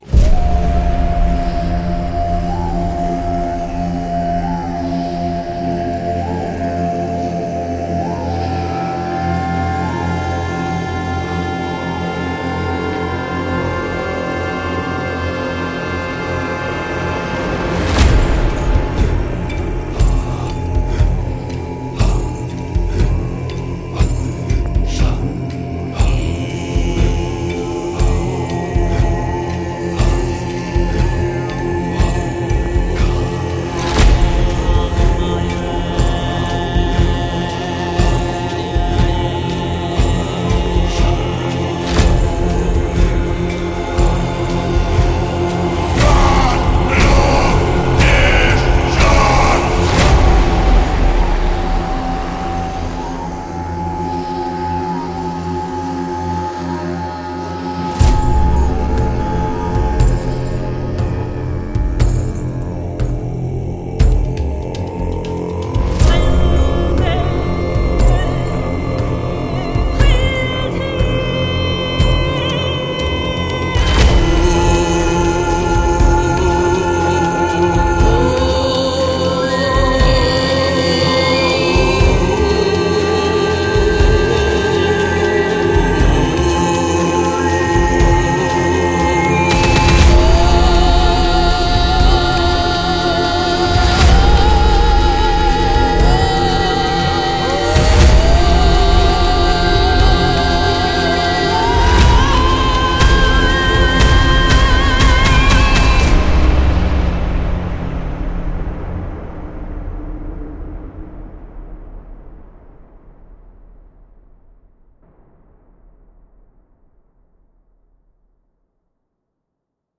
暗影之声 – 仪式之声和战争圣歌
我们记录了世界各地不同文化的许多传统演唱风格 ：图瓦喉咙和泛音演唱（Kaargyra，Khoomei和Szygyt）， 撒丁岛喉咙演唱（巴苏）、北欧库尔宁（高音女声）、斯拉夫歌唱（白人）、西方男中音、男高音和女高音，喉音，仪式吟唱甚至极端失真，高音技巧，尖叫，人声炸和咆哮.
结果是一组 8 种不同的可演奏 Kontakt 乐器 ，将所有这些声音组合在一起，创造出 各种各样的声音：从萨满低音人声无人机到神秘的节奏赞美诗，从空灵的女声合唱团 到激进的哈卡式圣歌，从僧侣哼唱的声音到 维京人提醒人声乐合奏。
Omen中的乐器立即唤起了被遗忘的寺庙中的庄严仪式，战场的尖叫声，北欧风景的空灵色彩，神秘的异教崇拜，巫术和巫术，树林里灵魂的窃窃私语。
打击乐循环基于一组 30 个速度同步鼓循环，分为 低鼓、中鼓和高鼓。
咆哮、窃窃私语和人声炸